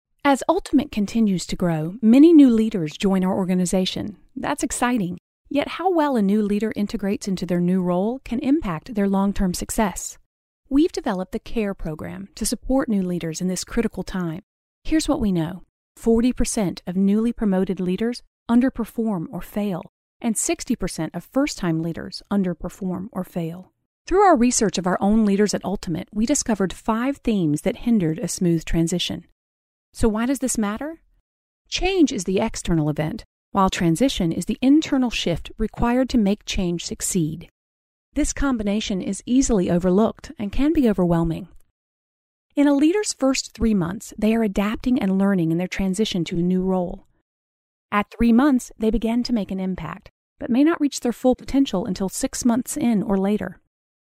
Female Voice Over, Dan Wachs Talent Agency.
Warm, Personal, Conversational, Motivating
eLearning